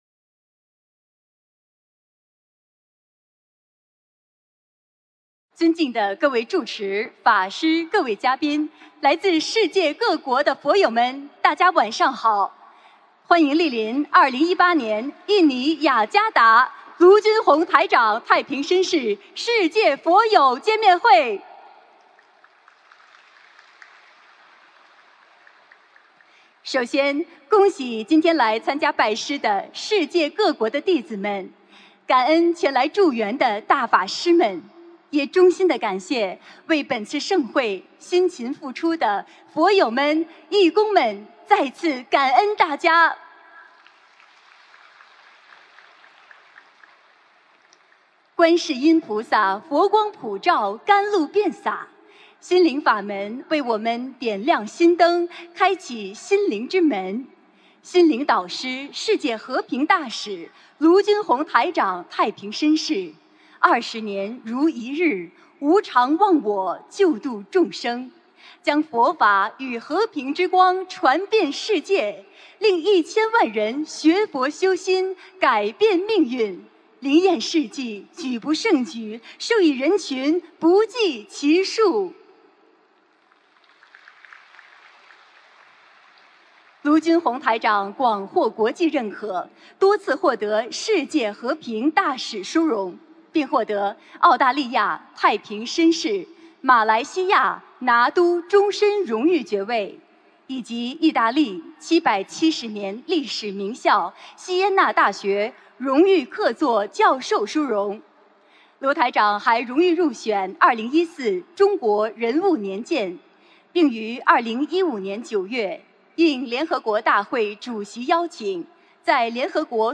2018年3月12日印尼雅加达见面会开示（视音文） - 2018年 - 心如菩提 - Powered by Discuz!